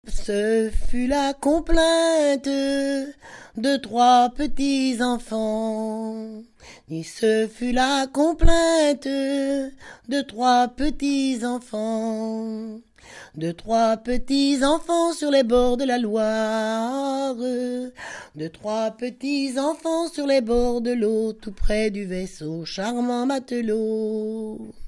Enquête Arexcpo en Vendée-Association Héritage-C.C. Herbiers
Pièce musicale inédite